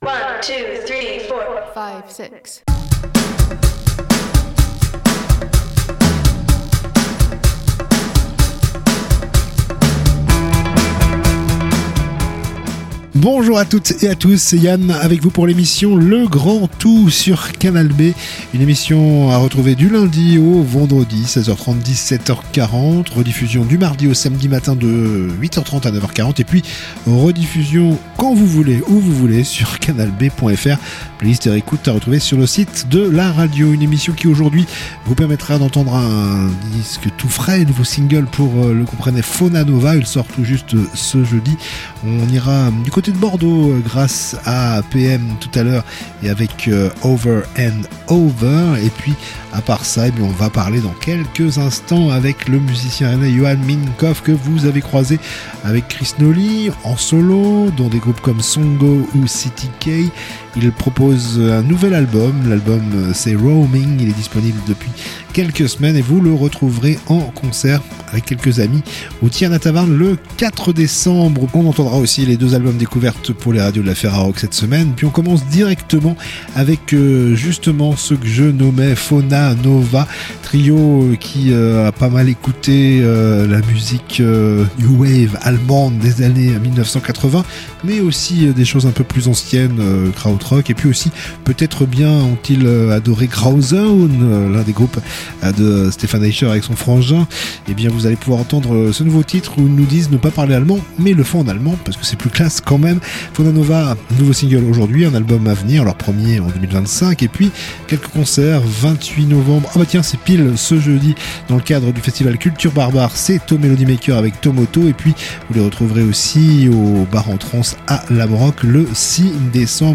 itv musique